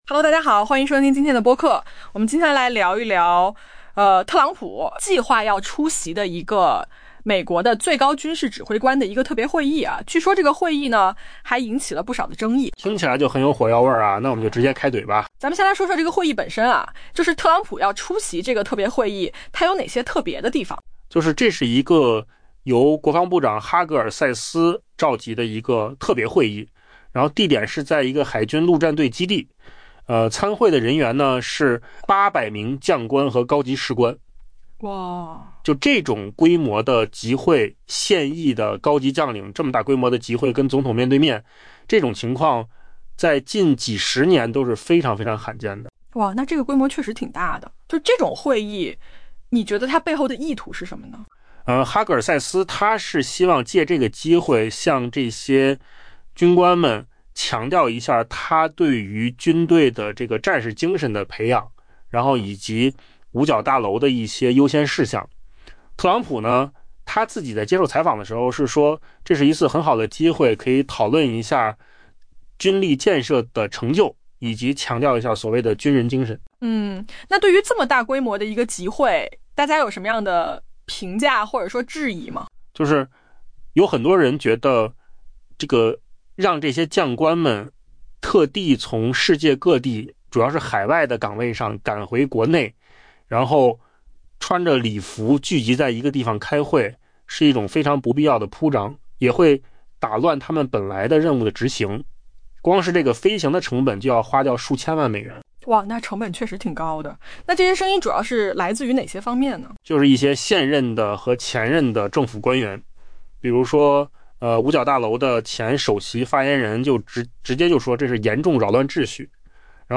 【文章来源：金十数据】AI播客：换个方
AI 播客：换个方式听新闻 下载 mp3 音频由扣子空间生成 美国总统特朗普计划出席国防部长赫格塞斯 （Pete Hegseth） 将于周二召集的美国最高军事指挥官特别会议。